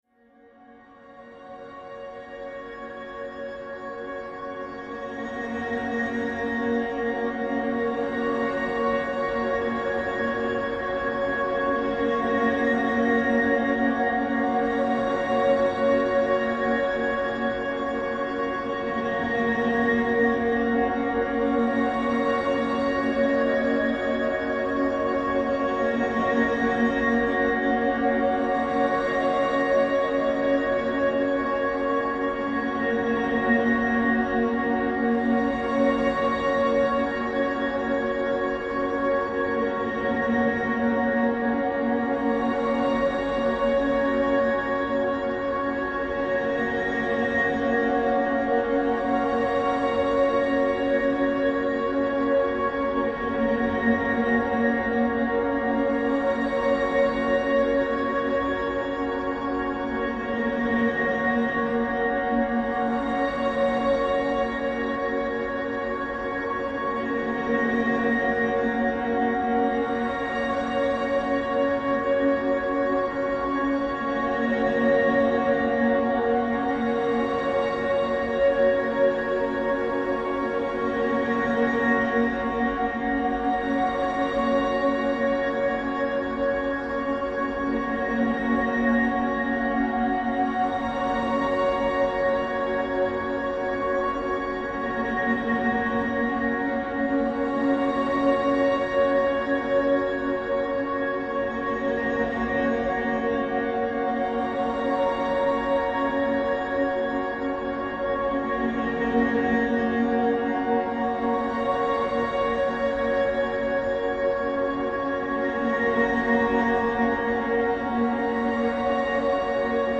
deze gerekte snede
Paul’s Extreme Sound Stretch